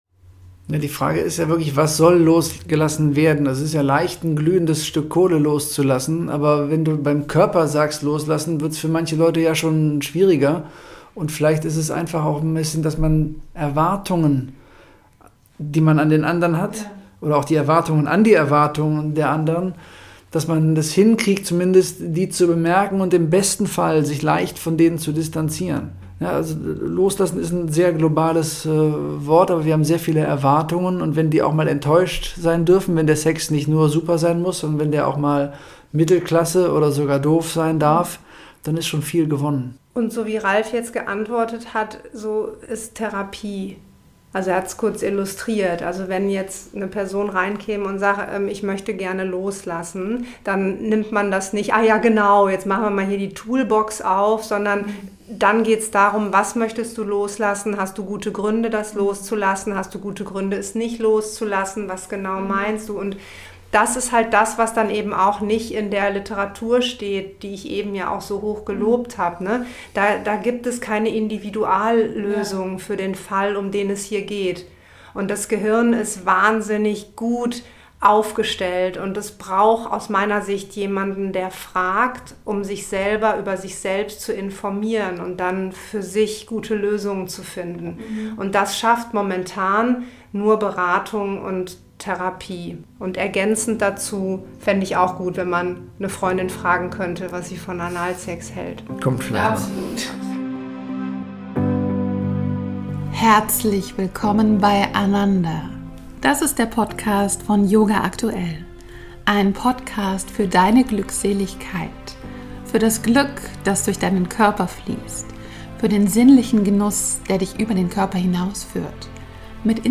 die Paar- und Sexualtherapeuten